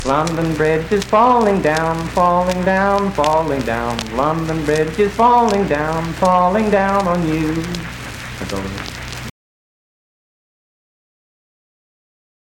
Unaccompanied vocal performance
Verse-refrain 1(4).
Children's Songs, Dance, Game, and Party Songs
Voice (sung)
Roane County (W. Va.), Spencer (W. Va.)